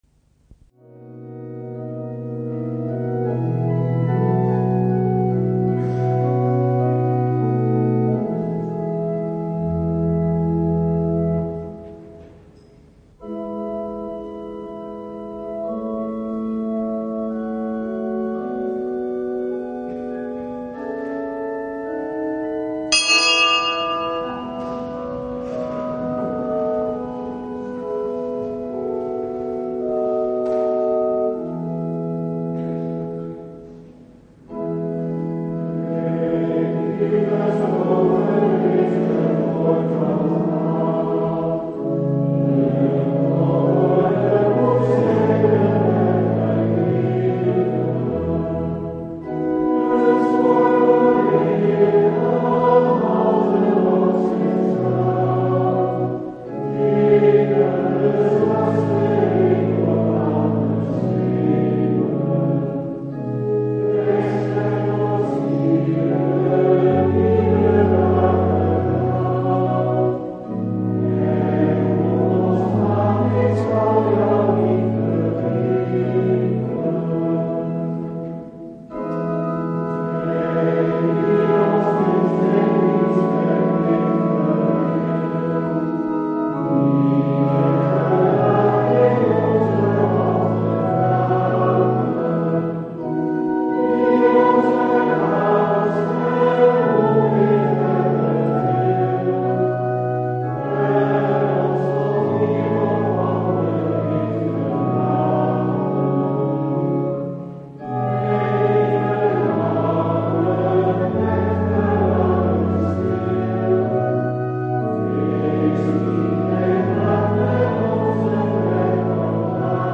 Lezingen
Eucharistieviering beluisteren (MP3)